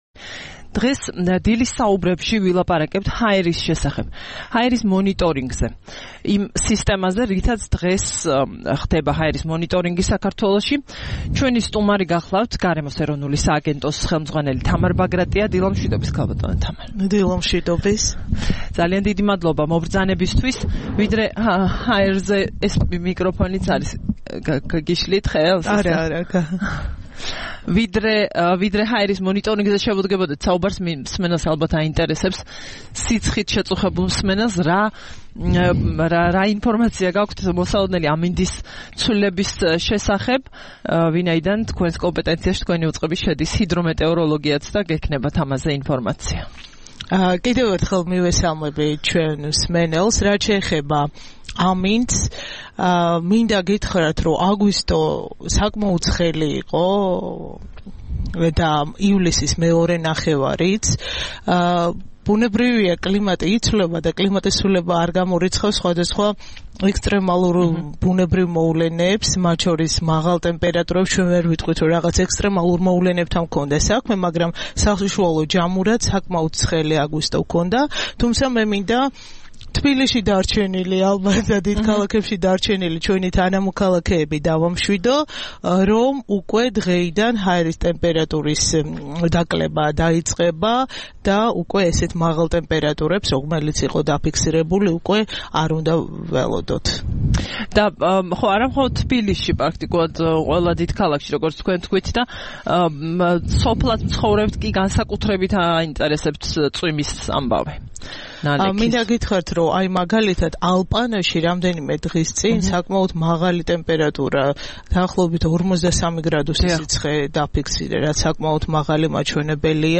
14 აგვისტოს რადიო თავისუფლების "დილის საუბრების" სტუმარი იყო თამარ ბაგრატია, გარემოს ეროვნული სააგენტოს უფროსი.